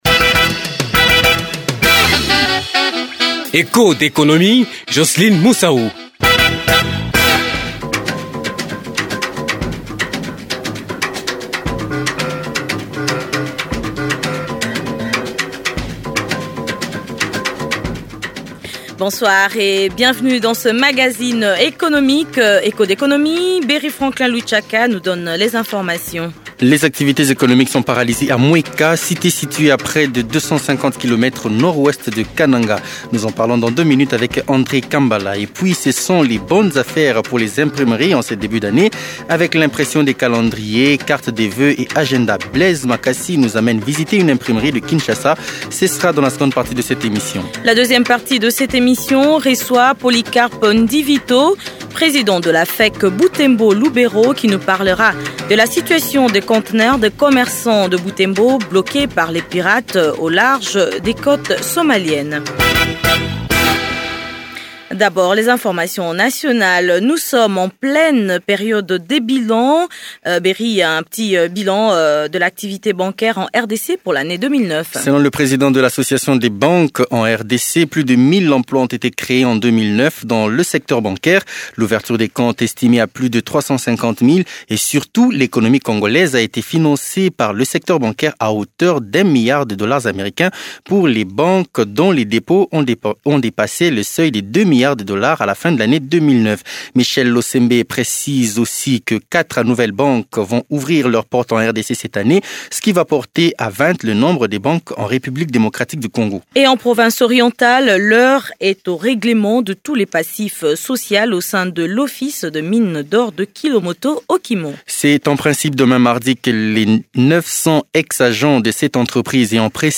C’est le reportage à suivre dans ce nouveau numéro de l’économie.